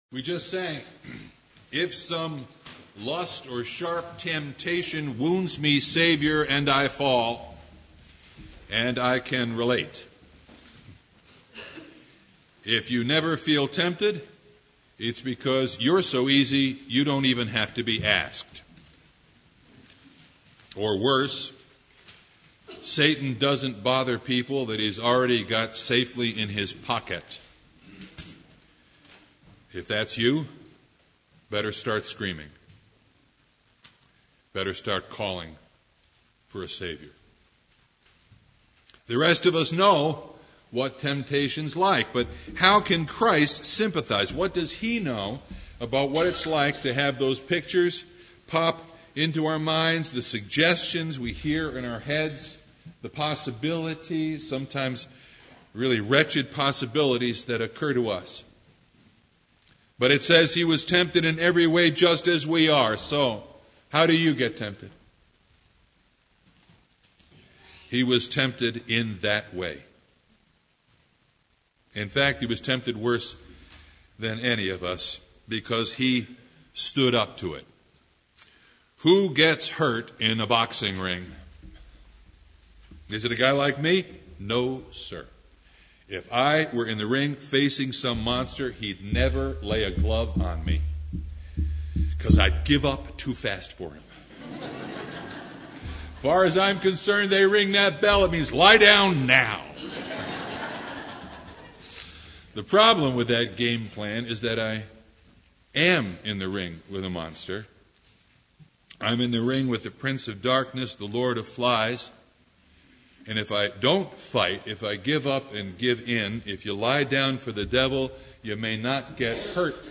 Service Type: Sunday
Sermon Only